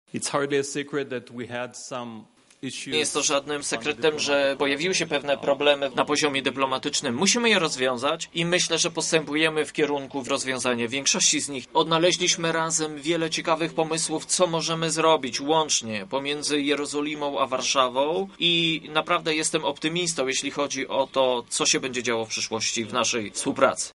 Moim celem jest przywrócenie relacji między Polską a Izraelem – mówi ambasador Yacov Livne: